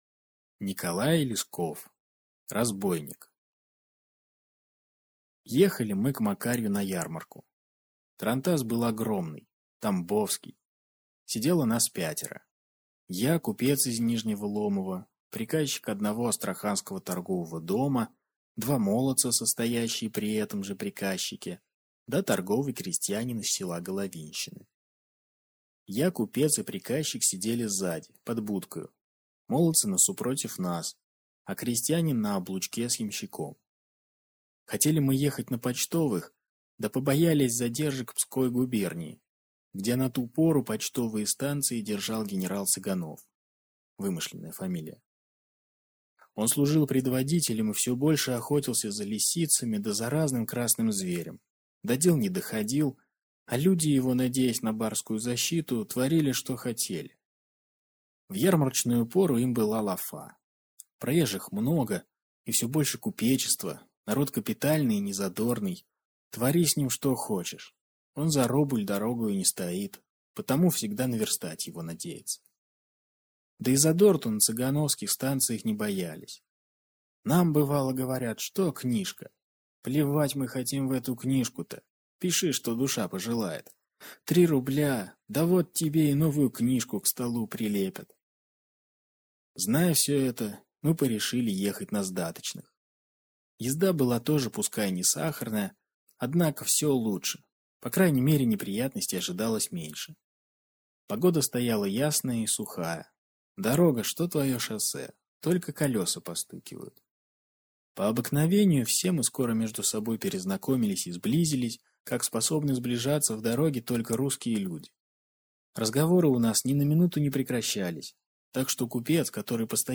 Аудиокнига Разбойник | Библиотека аудиокниг